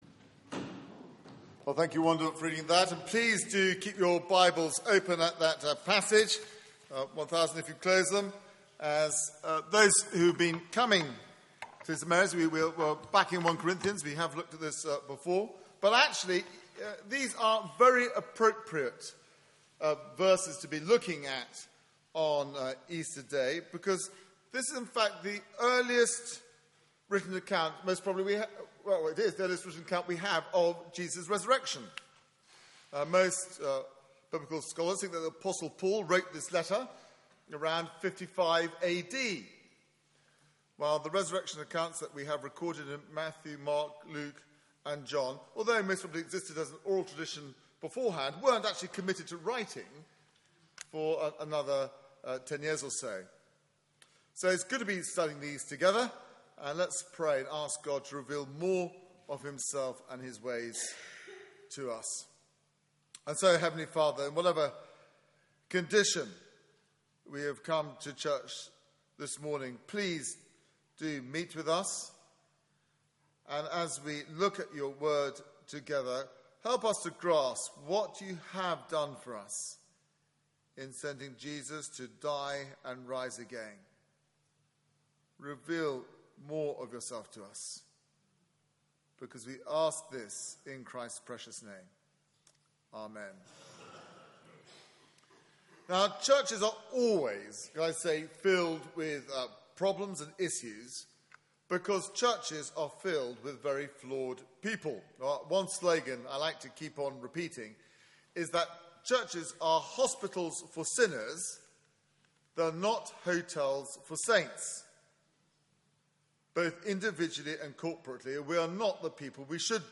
Media for 9:15am Service on Sun 27th Mar 2016 09:15 Speaker
Theme: Of first importance Sermon